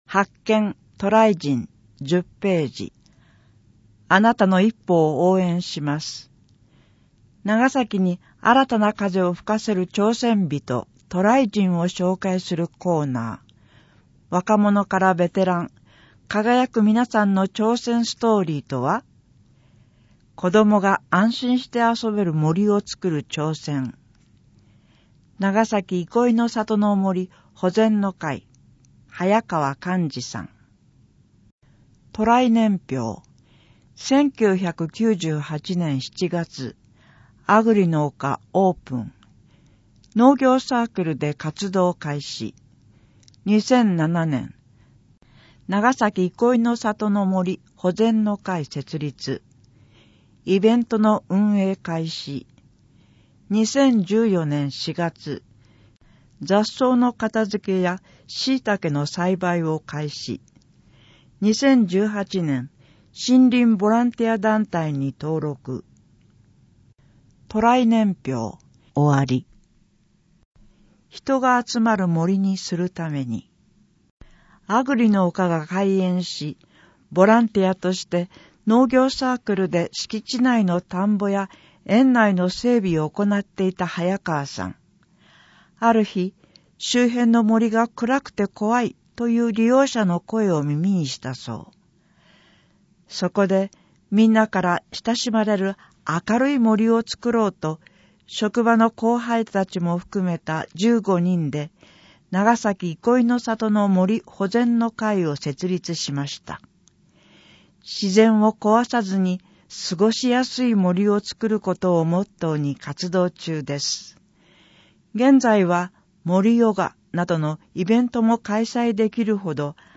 声の広報ながさき 令和6年9月号 No.884